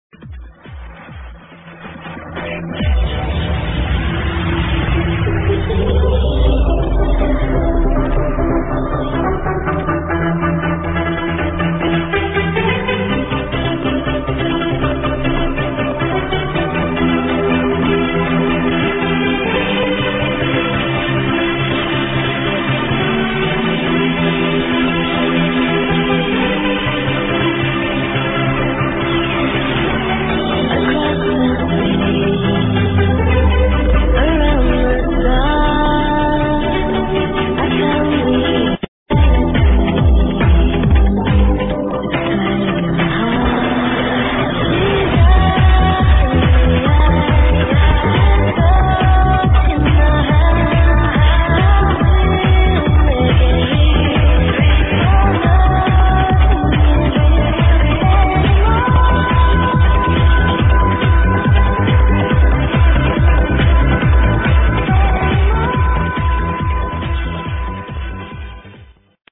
i'm not sure because it soudns fucked up